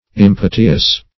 Impiteous \Im*pit"e*ous\, a. Pitiless; cruel.
impiteous.mp3